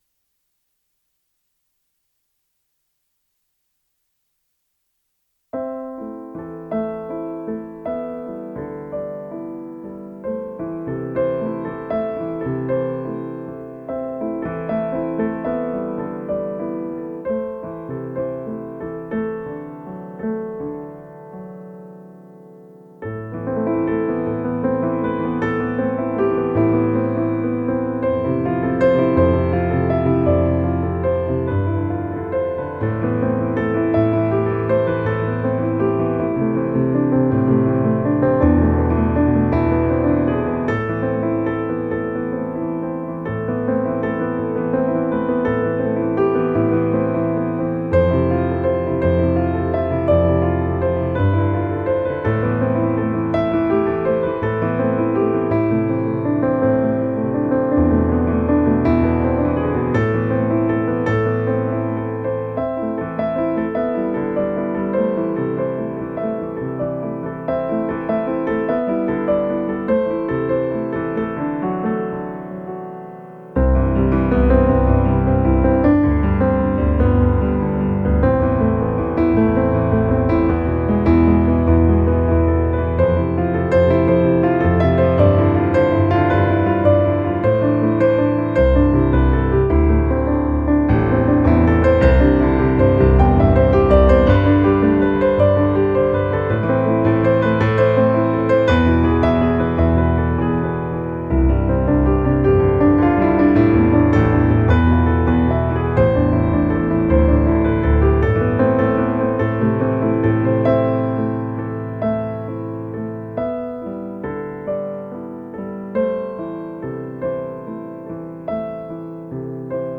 電鋼琴最棒的是，可以 line out 到我的錄音筆，所以先錄了一下來強迫自己專注。